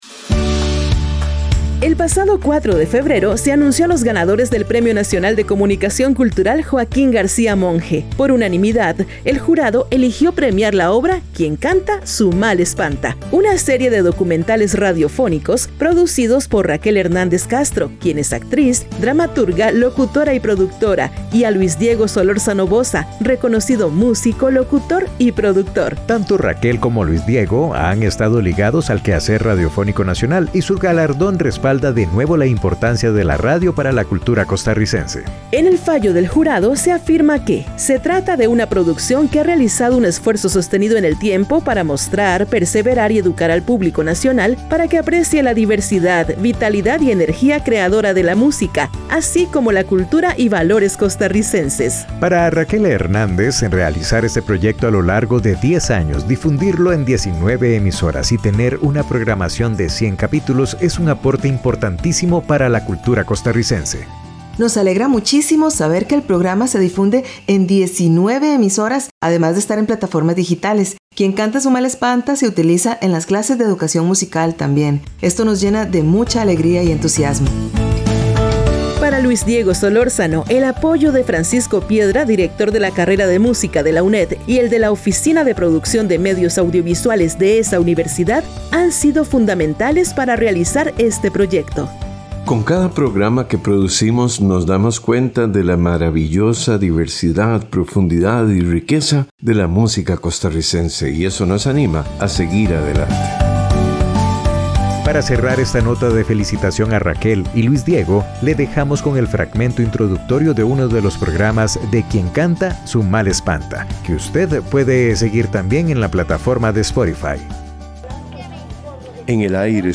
una serie de documentales radiofónicos